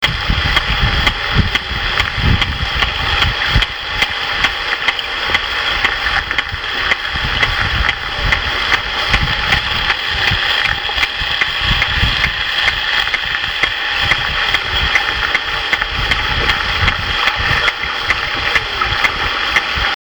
Wir haben mehrfach tauchende Wale mit einem Hydrophon verfolgt. Unmittelbar nach dem Abtauchen setzen die regelmäßigen Klicks ein.
Pottwalbullen klicken erheblich lauter und weniger oft.